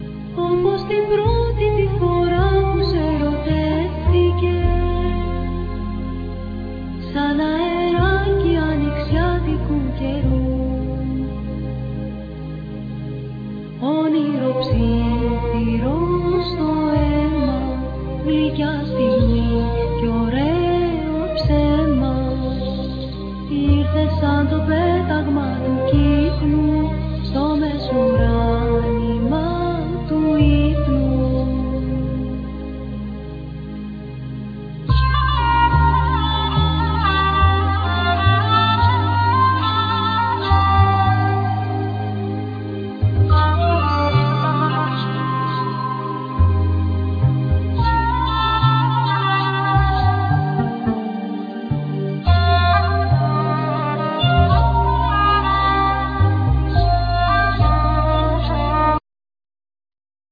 Nylon string guitar,Mandokino,Bass
Vocals
Lyra
Piano,Keyboards
Drums,Percussions